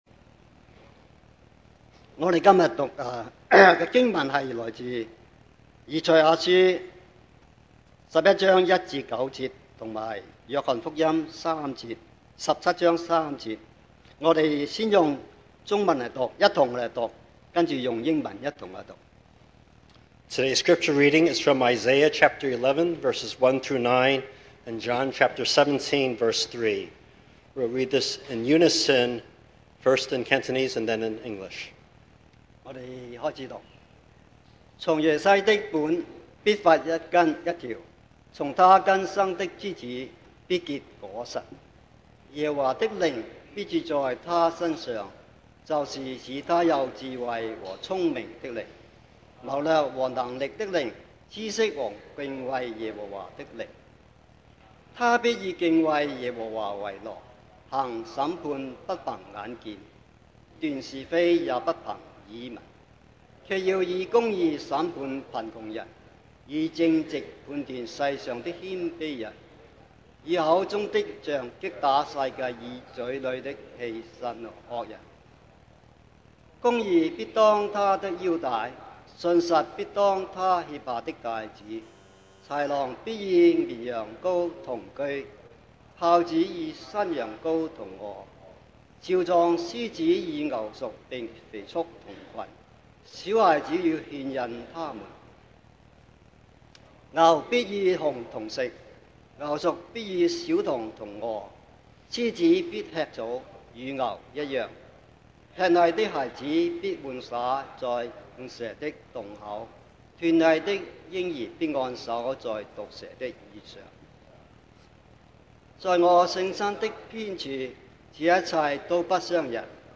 2024 sermon audios 2024年講道重溫 Passage
John 17:3 Service Type: Sunday Morning What is Eternal Life?